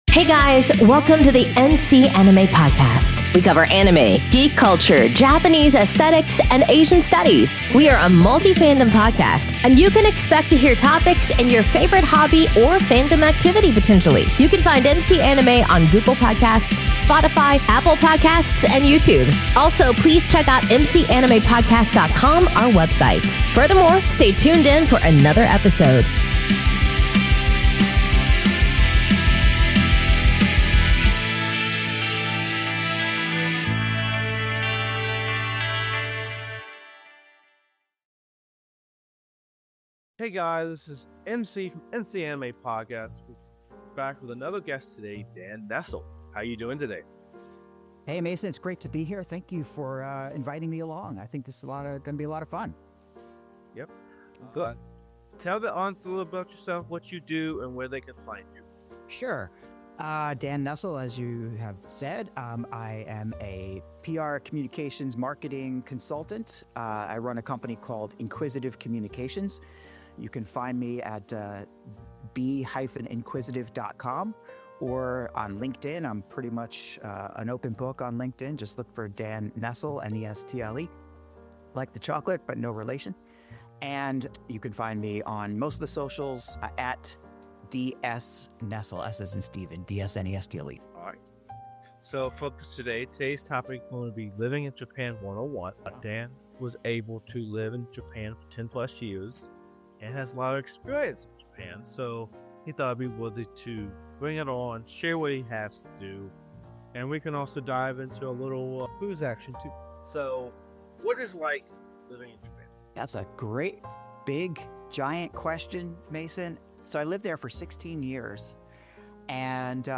Welcome to our fandom-centric podcast, where we explore anime, geek culture, and dive deep into Japanese aesthetics and Asian Studies. Our family-friendly program features open forum discussions with a conversational, casual tone, offering unique perspectives on the topics that matter most.